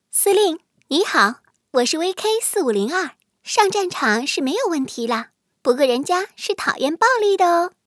VK4502-获得对白.mp3